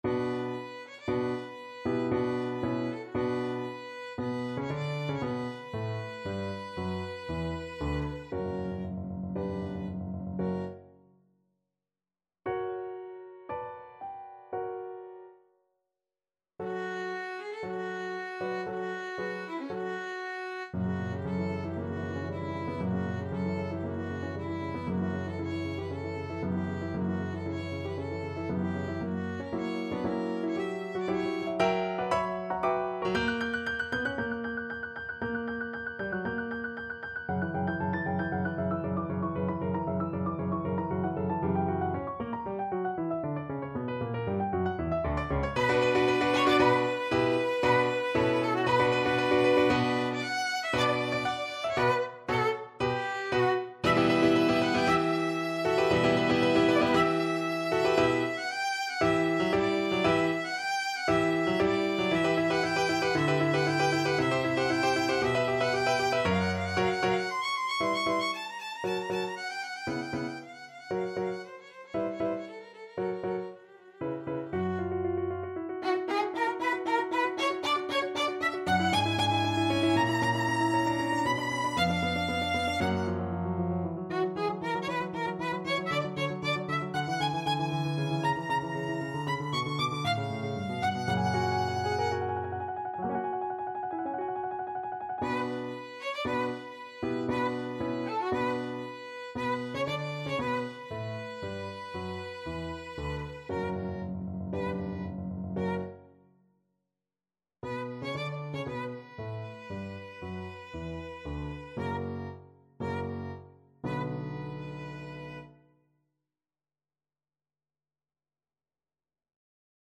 Classical Dvořák, Antonín Concerto for Cello Op.104, 1st Movement Main Theme Violin version
Violin
B minor (Sounding Pitch) (View more B minor Music for Violin )
4/4 (View more 4/4 Music)
Allegro =116 (View more music marked Allegro)
Classical (View more Classical Violin Music)